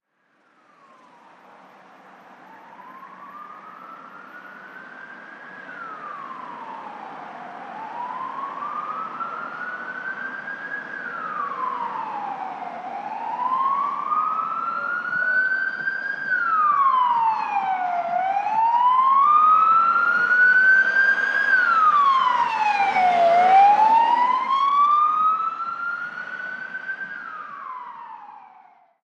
Ambulancia pasando con sirena
Sonidos: Transportes Sonidos: Ciudad